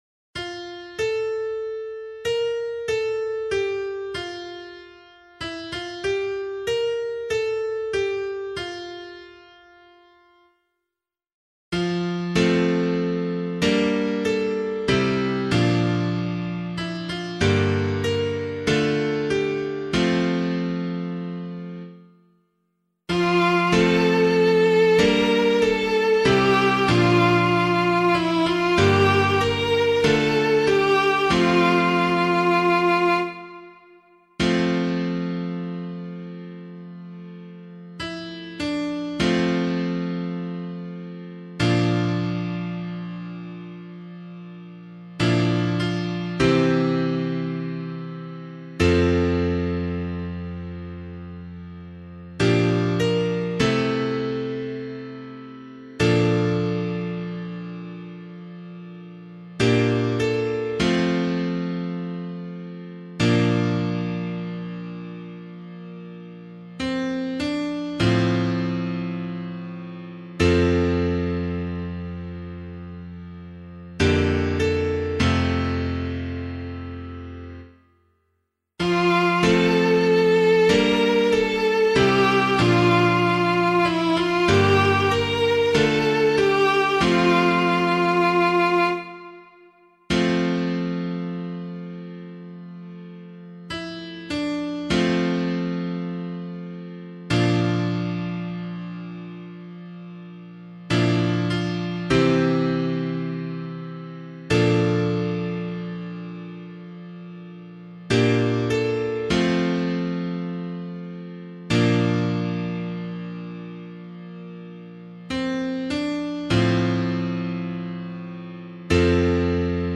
026 Easter 4 Psalm A [Abbey - LiturgyShare + Meinrad 6] - piano.mp3